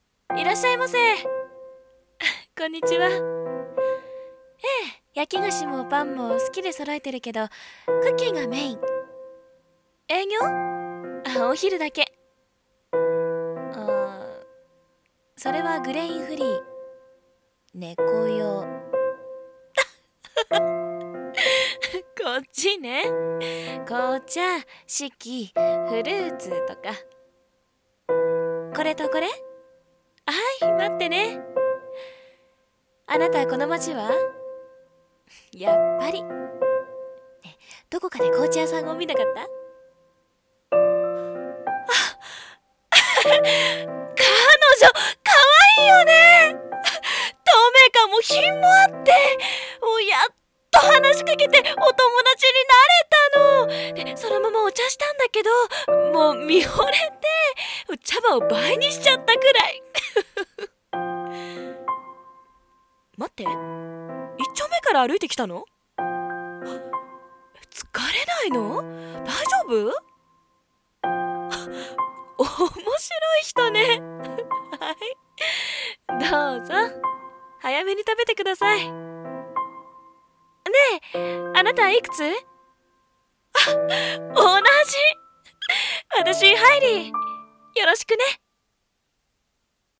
【声劇】 お昼のクッキー屋